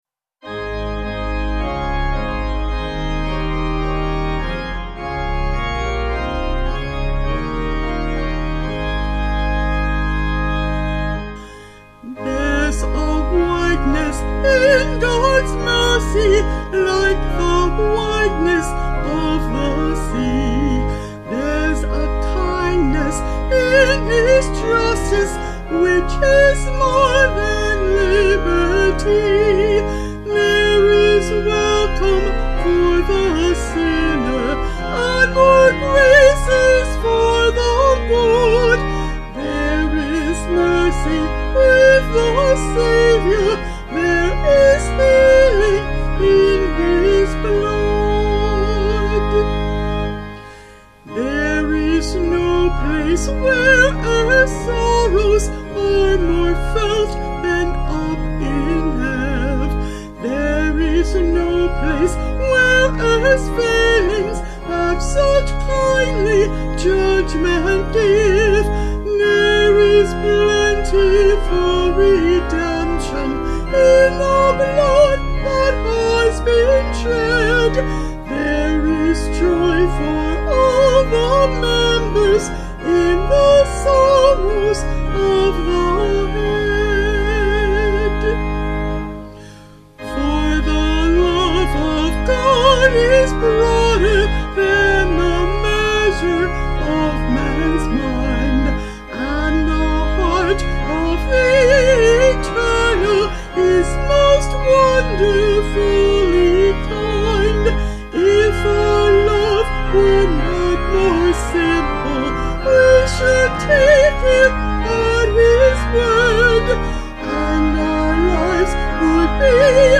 Vocals and Organ   230kb Sung Lyrics